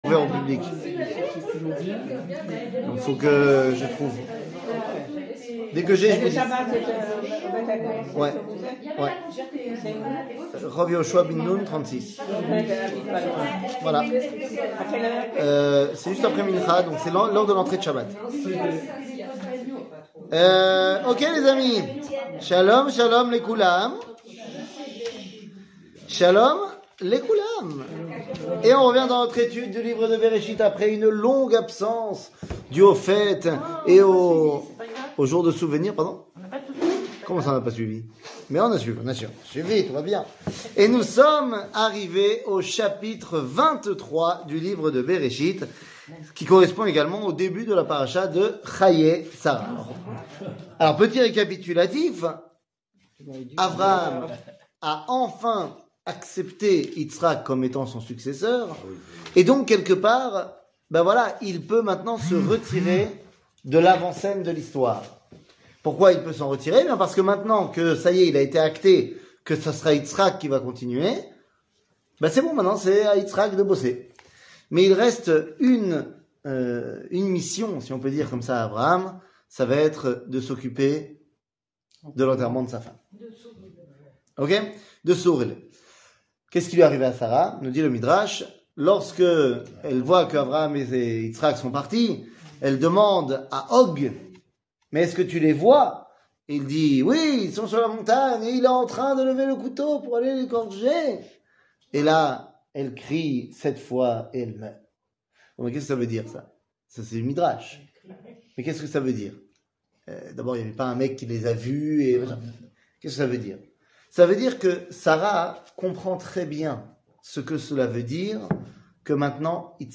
קטגוריה t 00:53:13 t שיעור מ 11 מאי 2022 53MIN הורדה בקובץ אודיו MP3